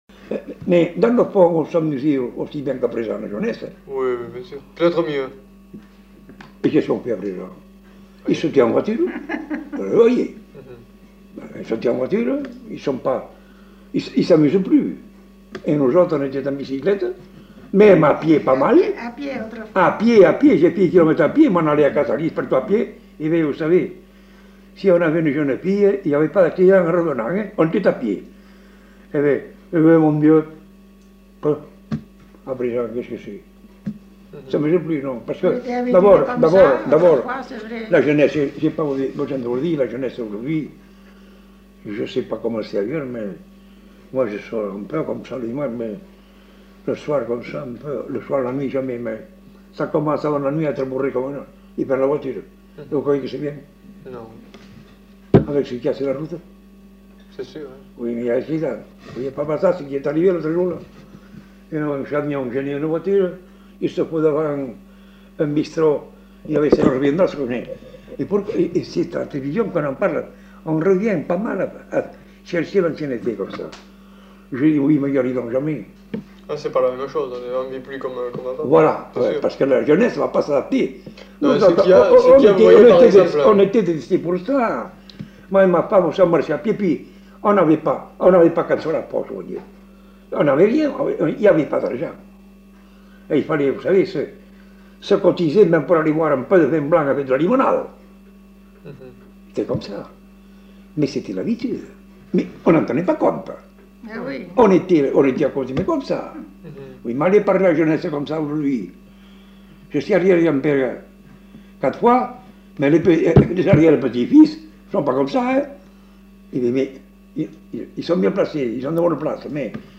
Aire culturelle : Bazadais
Genre : témoignage thématique